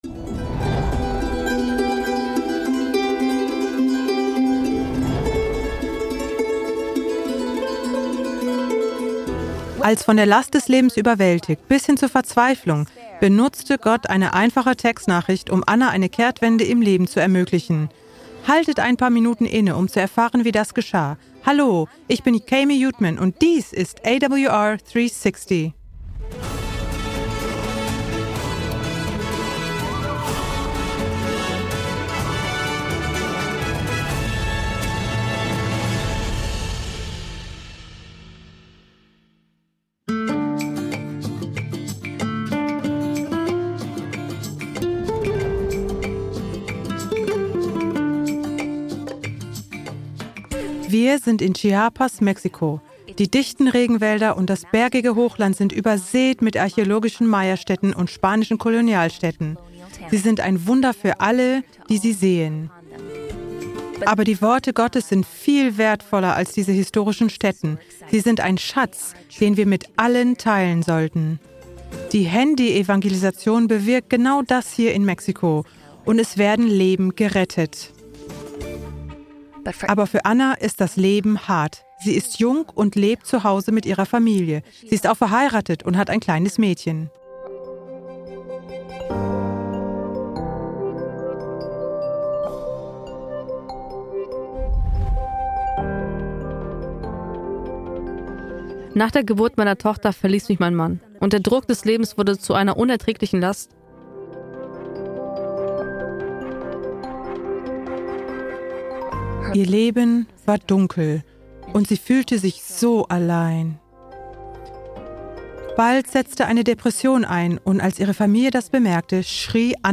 Kategorie Zeugnis